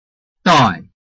拼音查詢：【饒平腔】doi ~請點選不同聲調拼音聽聽看!(例字漢字部分屬參考性質)